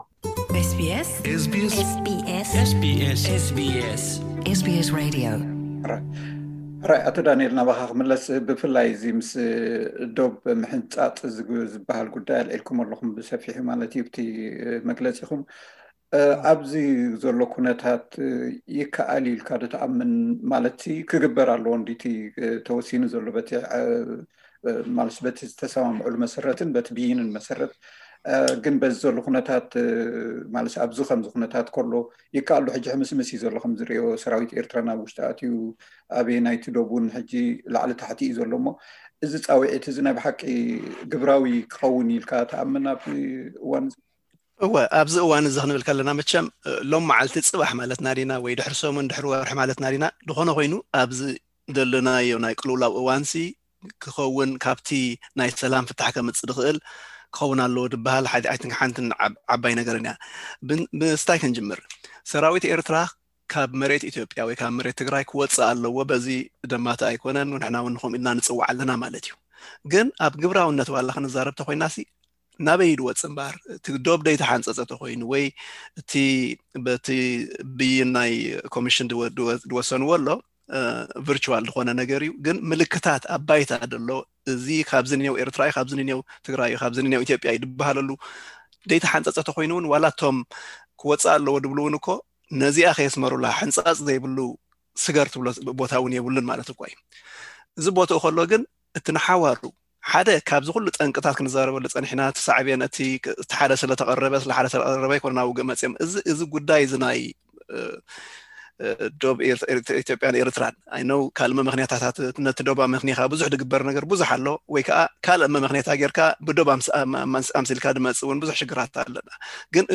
ካል ኣይ ክፋል ዘተ